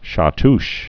(shä-tsh)